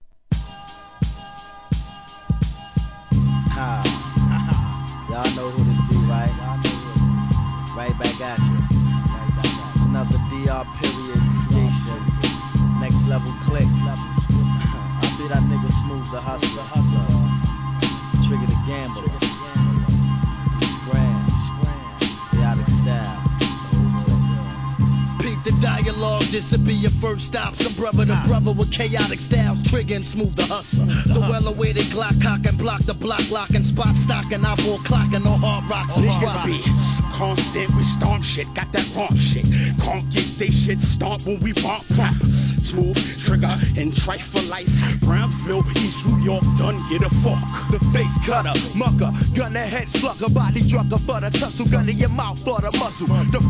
1. HIP HOP/R&B
1995年、ハーコー・アングラ!!